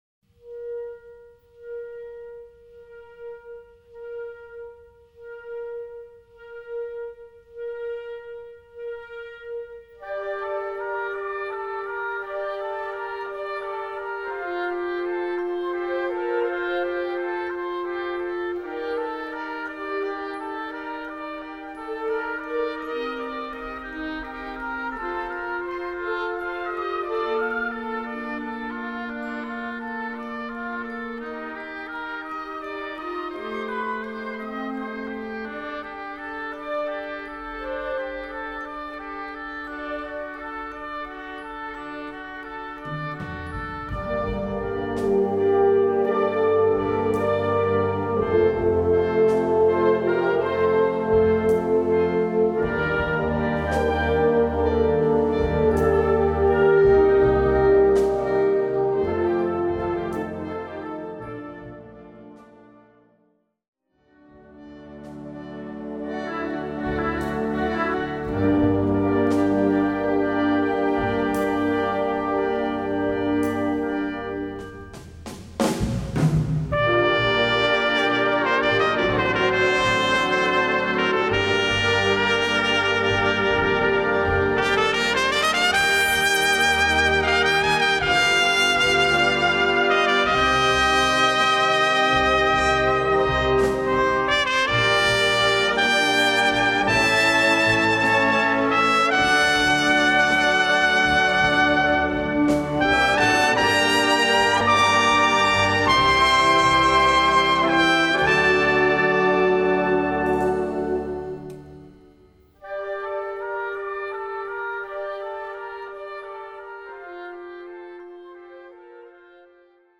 Solo für Flöte, Klarinette oder Altsaxophon
Besetzung: Blasorchester